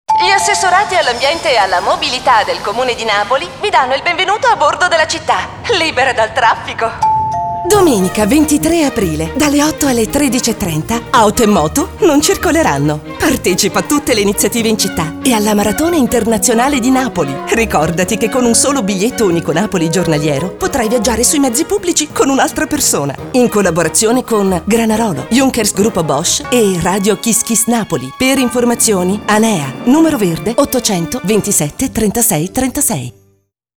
Spot 23 Aprile.mp3